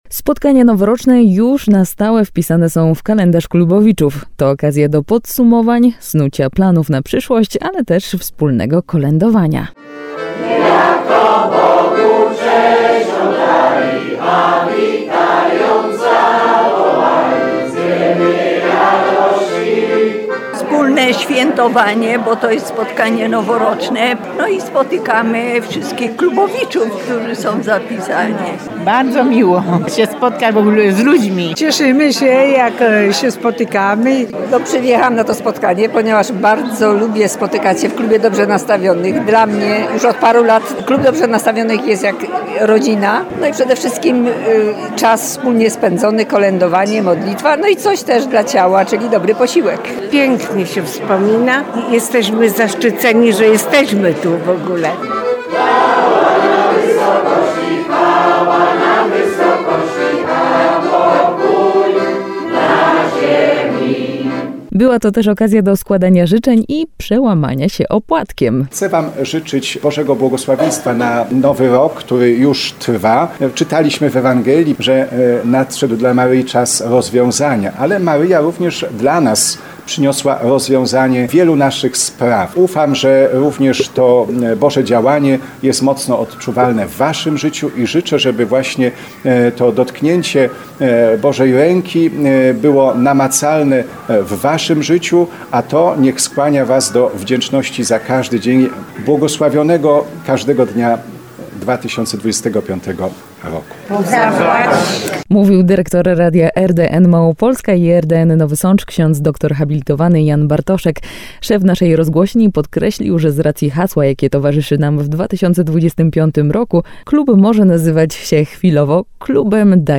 Zobacz zdjęcia: Sądecki Klub Dobrze Nastawionych spotkał się na opłatku.
Stali słuchacze i sympatycy radia RDN Nowy Sącz składali sobie życzenia i kolędowali.